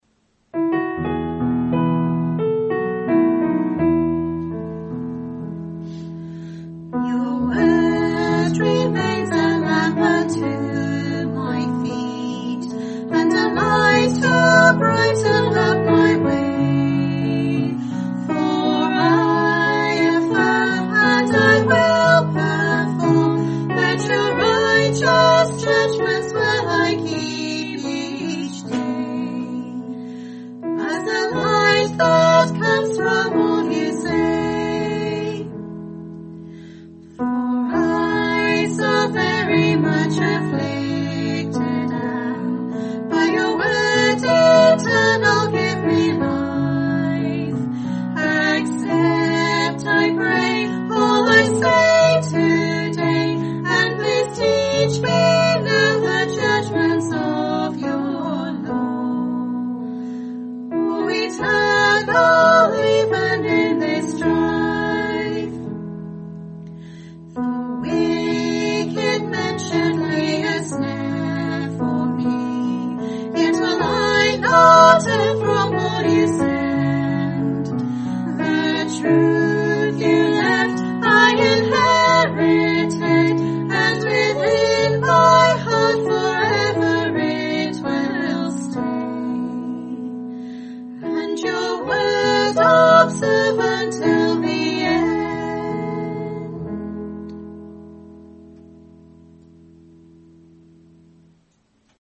Sermonette
given Wales UK 8 Apr 2026